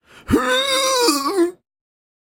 get-sick.ogg.mp3